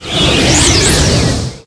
Magic6.ogg